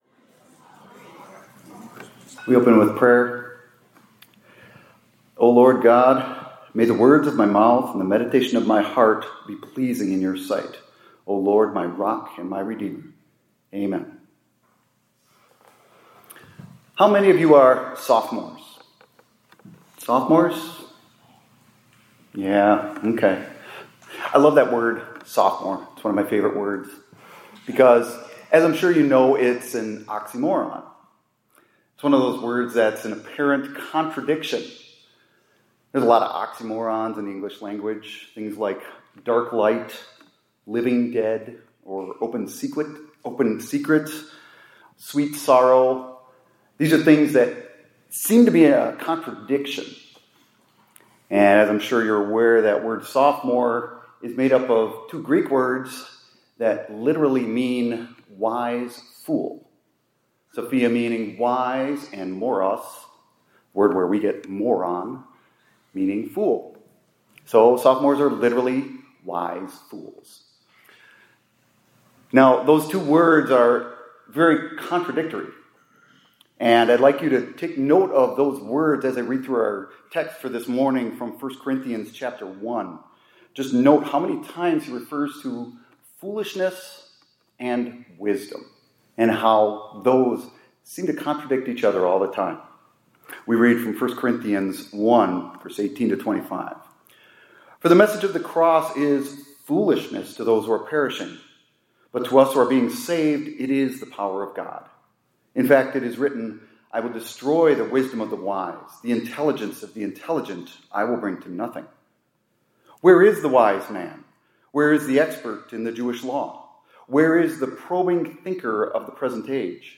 2025-09-26 ILC Chapel — Christians Are Wise Fools